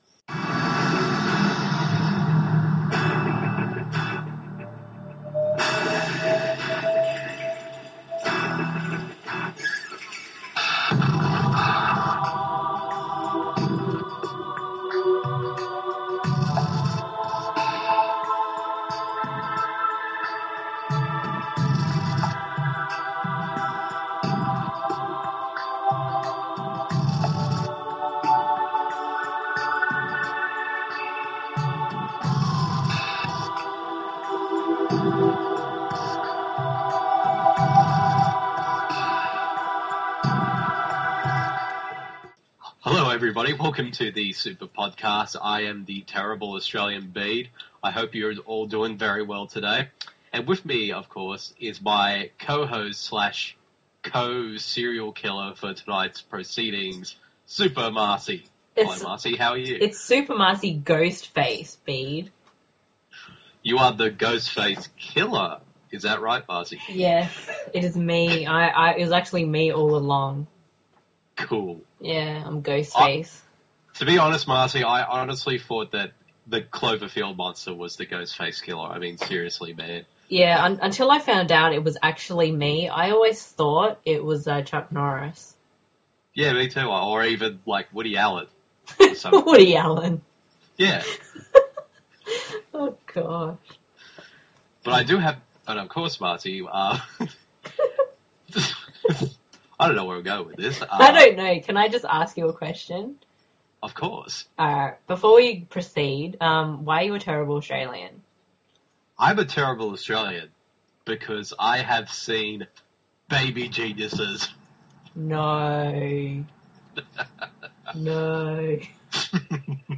(Just take note, we had some major tech issues so there are some sound delays) Highlights include: * We discuss the original Scream, and what impact it had.